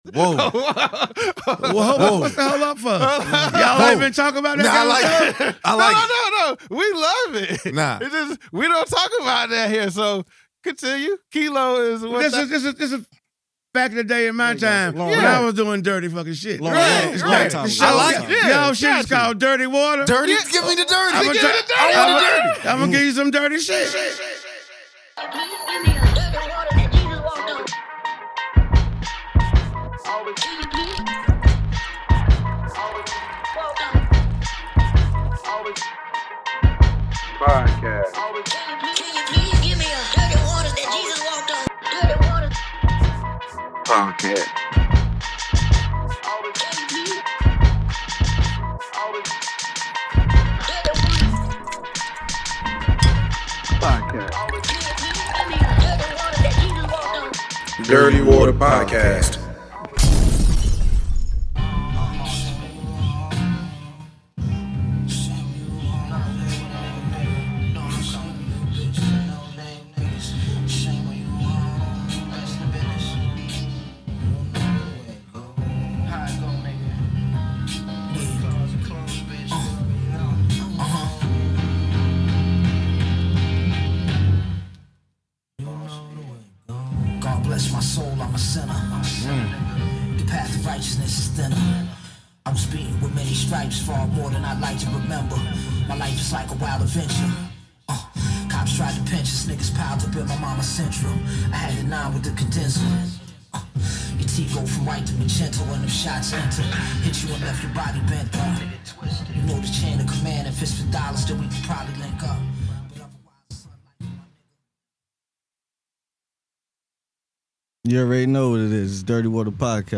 This time they call to check on or their family and friends to see how things are going during the COVID Pandemic!!